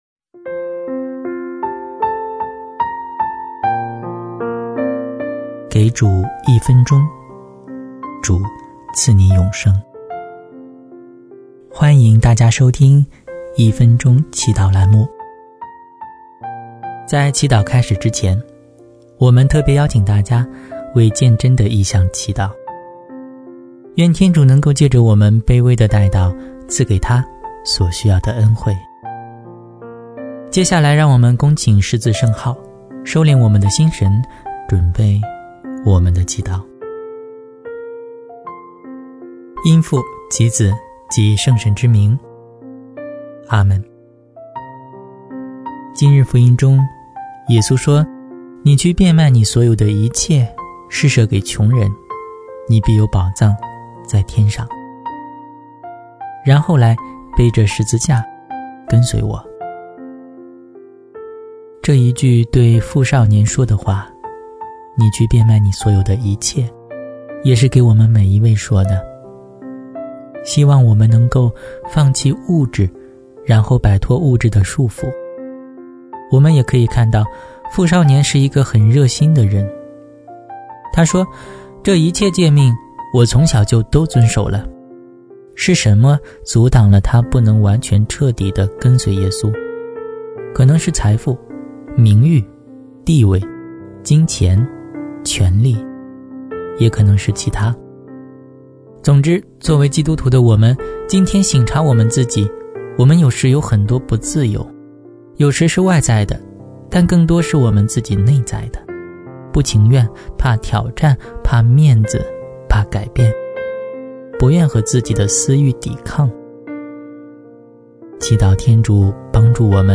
10月10日祷词